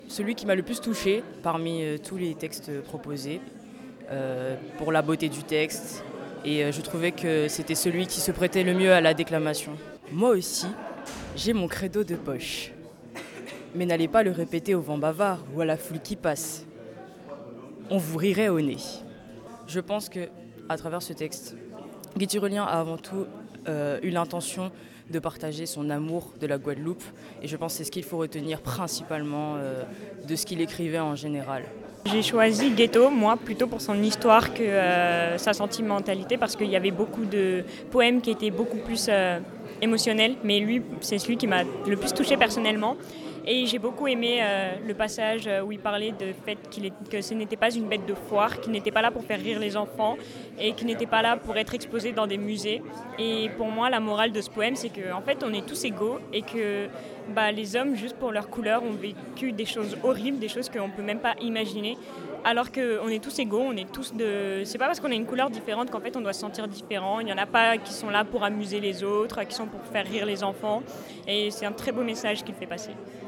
ITW deux étudiantes CPGE AL 1ère année